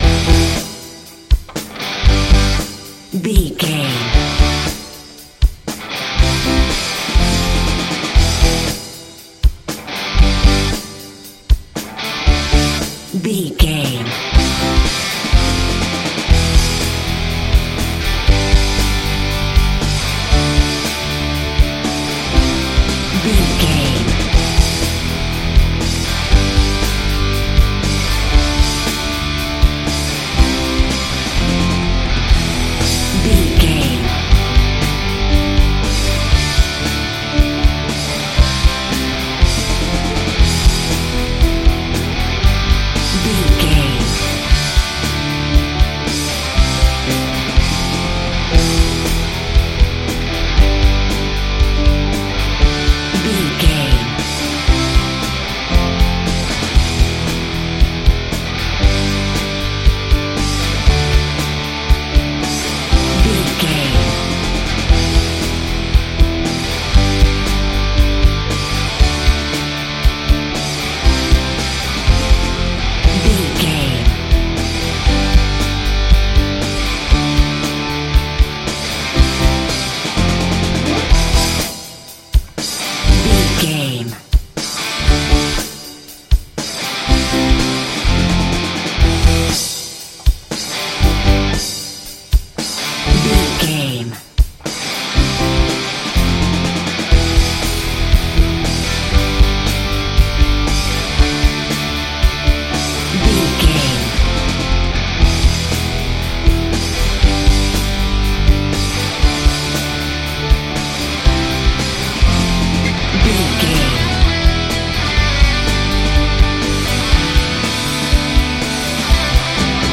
Aeolian/Minor
drums
electric guitar
bass guitar
hard rock
lead guitar
aggressive
energetic
intense
nu metal
alternative metal